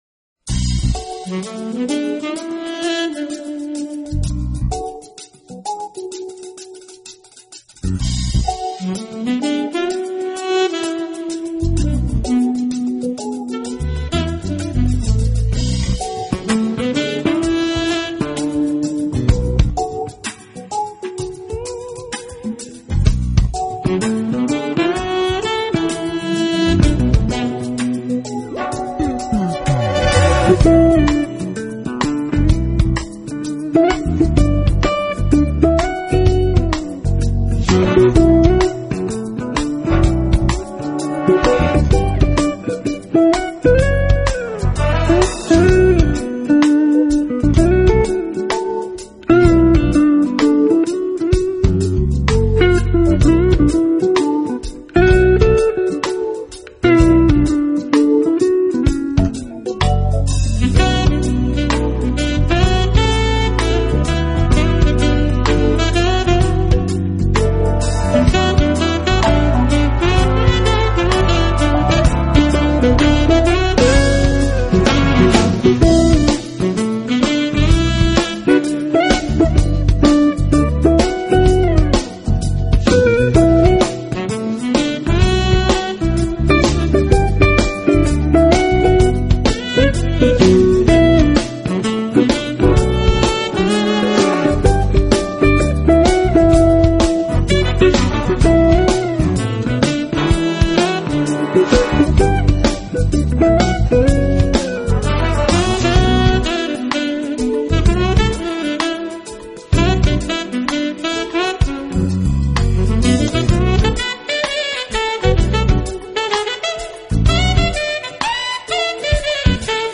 专辑类型：JAZZ